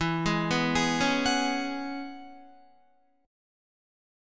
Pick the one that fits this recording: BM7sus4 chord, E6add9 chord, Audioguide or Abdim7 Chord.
E6add9 chord